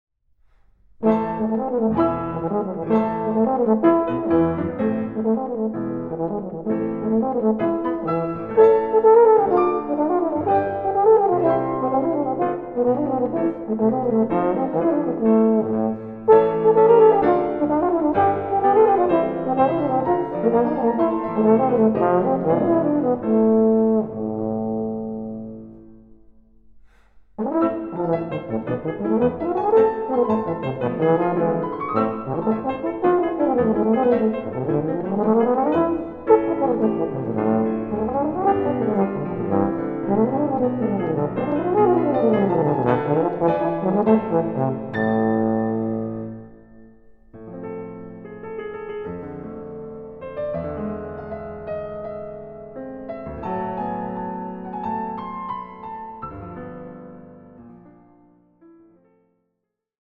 Aufnahme: Mendelssohn-Saal, Gewandhaus Leipzig, 2025
Version for Euphonium and Piano